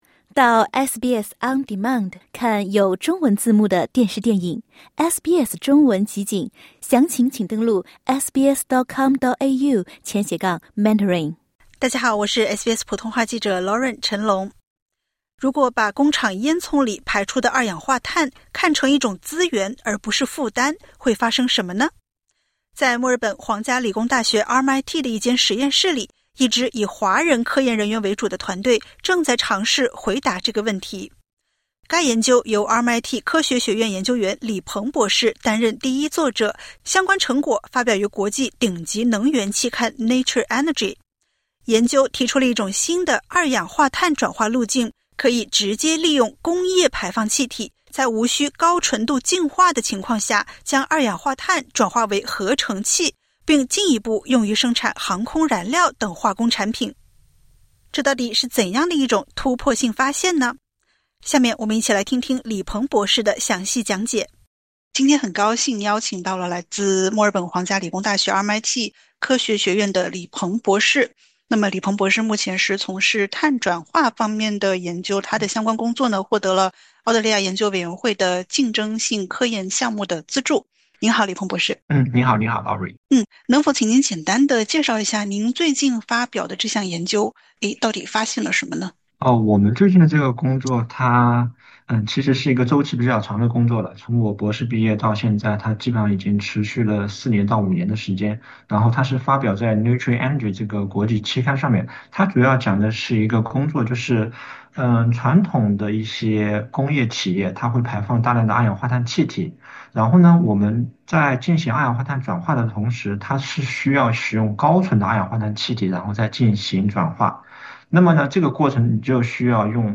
如果把工厂烟囱里排出的二氧化碳，不再只当作污染，而是当成一种可以再利用的资源，会发生什么？一支以华人科研人员为主的研究团队，正在尝试为这个问题寻找新的答案。点击 ▶ 收听完整采访。